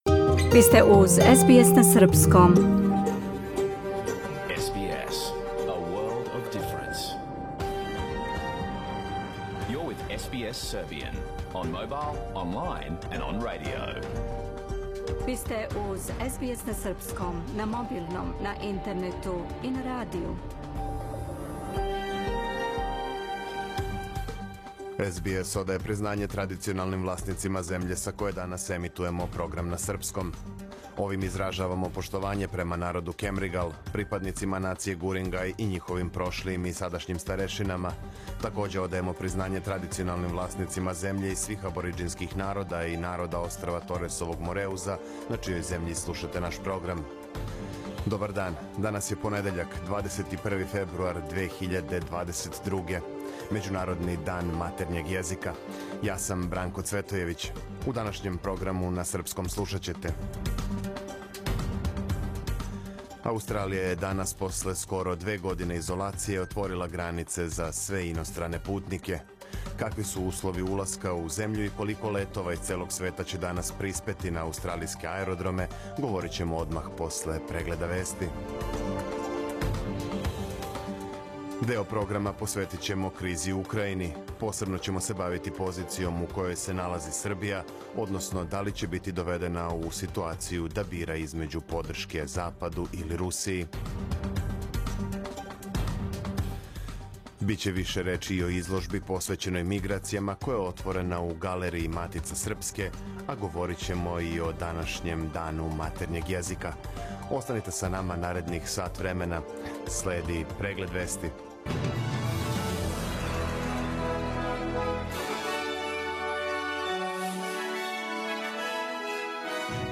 Програм емитован уживо 21. фебруара 2022. године